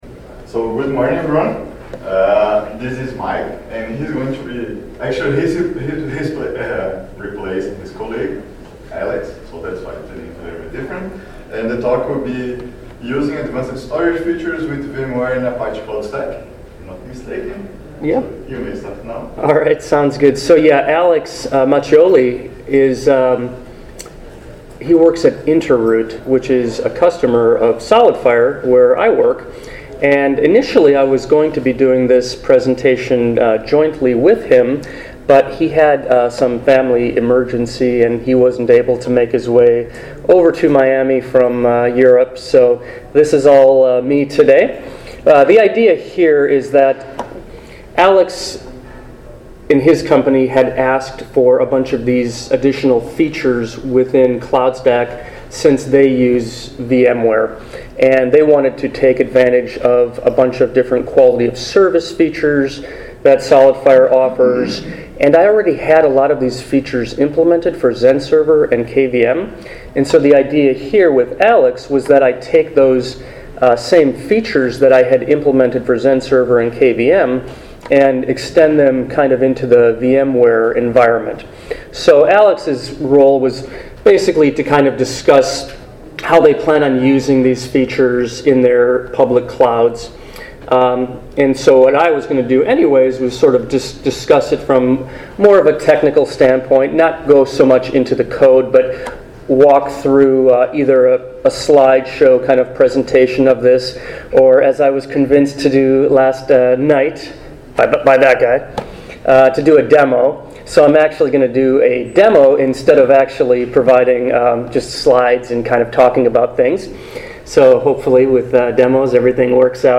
ApacheCon Miami 2017
SolidFire Cloudstack Collaboration Conference